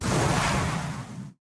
foundry-fire-whoosh-3.ogg